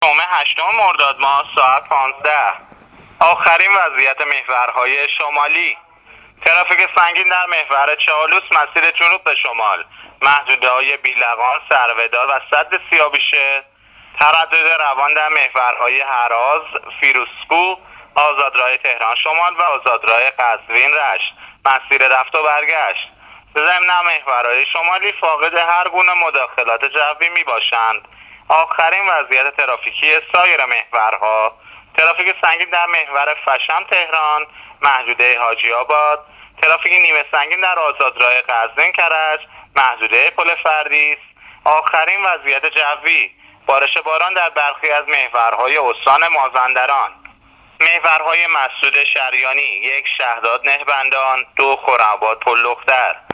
گزارش رادیو اینترنتی از آخرین وضعیت ترافیکی جاده‌ها تا ساعت ۱۵ هشتم مرداد